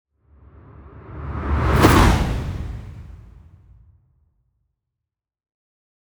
TRANSITION Whoosh Big Subtle Tonal.wav